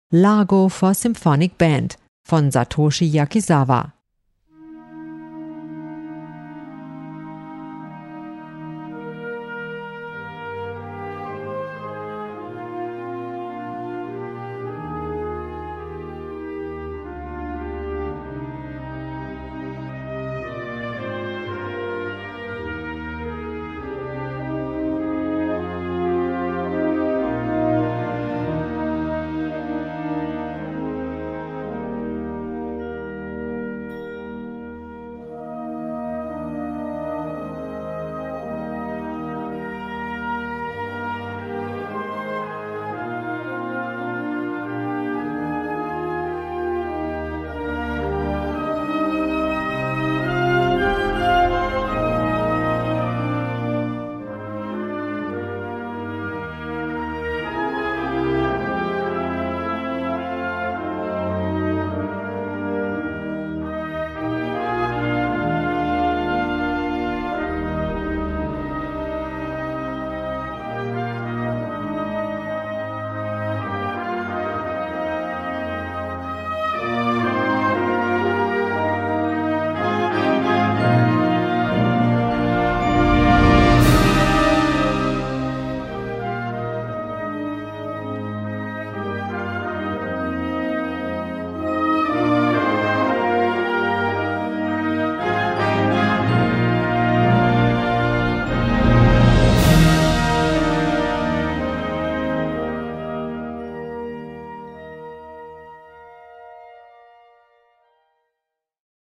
Besetzung: Blasorchester
das mit innigem Gefühl gespielt werden konnte.
warm und sanft klingende